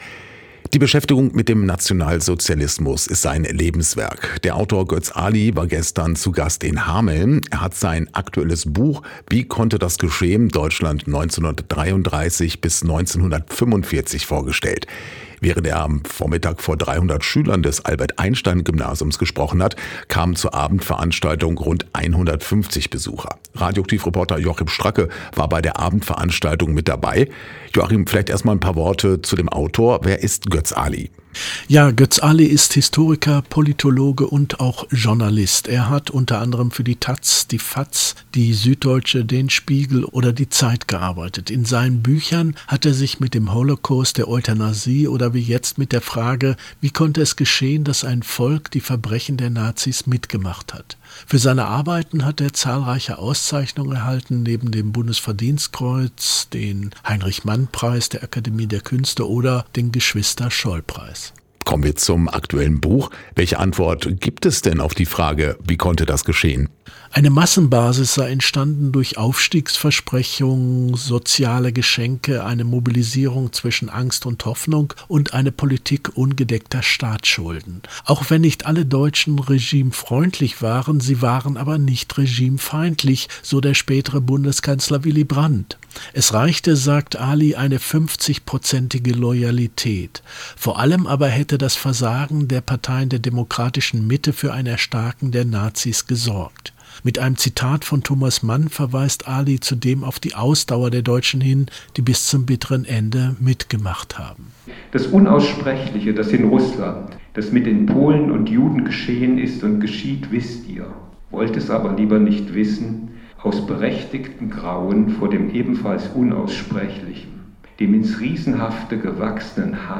Lesung mit Götz Aly
Der Autor Götz Aly war zu Gast in Hameln. Er hat sein aktuelles Buch: Wie konnte das geschehen – Deutschland 1933 bis 1945 vorgestellt.